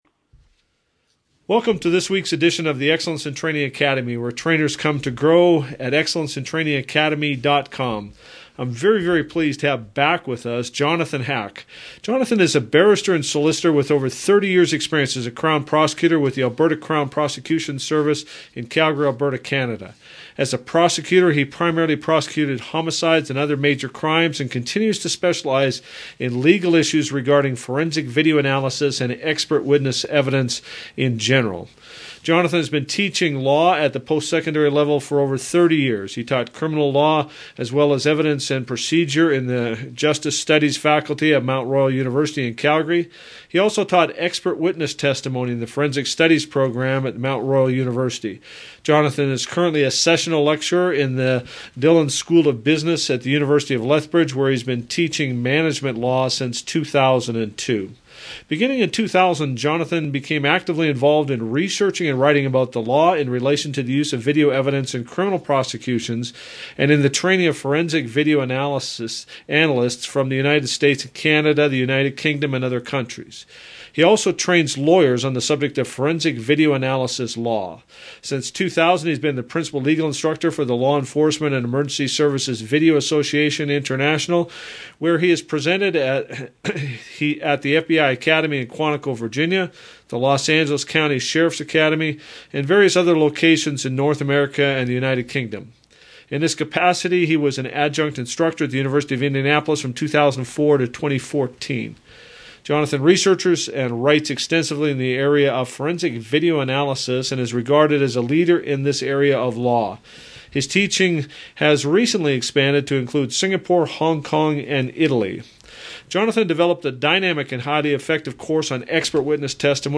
Audio Interview About Digital Video Evidence
Interview-on-Digital-Video-Evidence.mp3